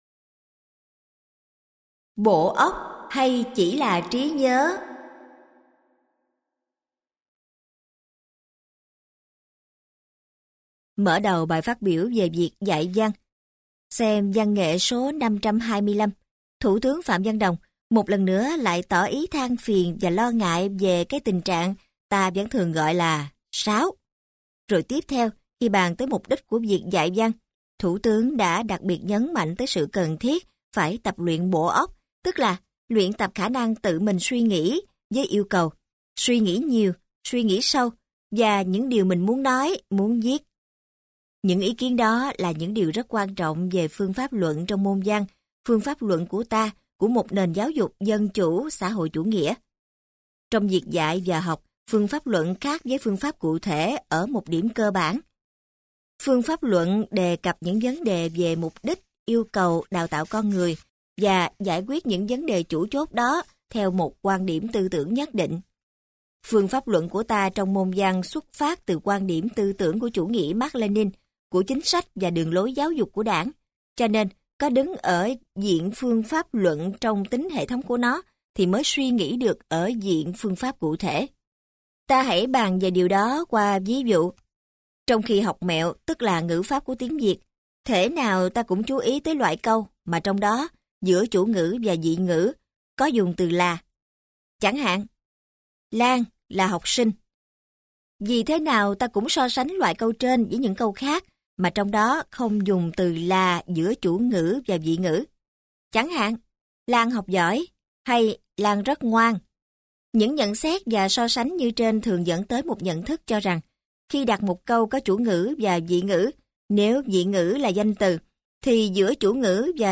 Sách nói Cuộc sống ở trong Ngôn Ngữ - Hạt Giống Tâm Hồn - Sách Nói Online Hay